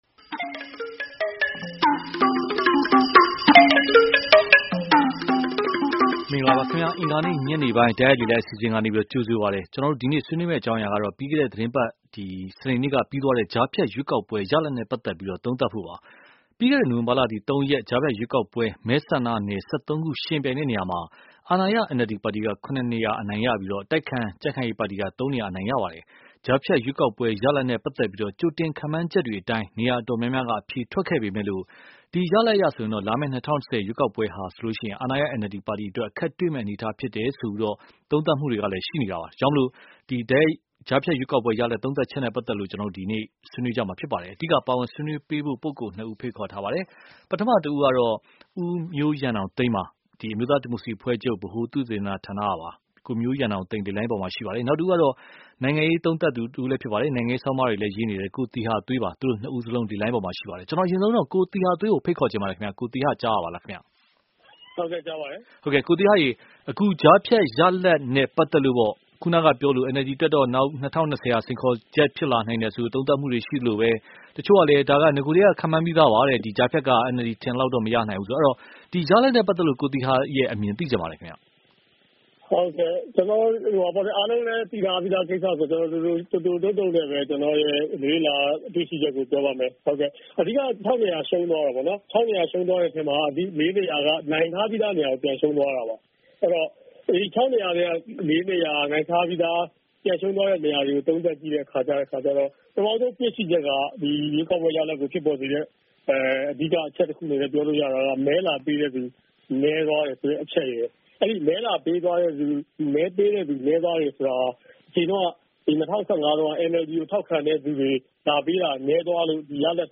ကြားဖြတ်ရွေးကောက်ပွဲ ရလဒ် သုံးသပ်ချက် (တိုက်ရိုက်လေလှိုင်း)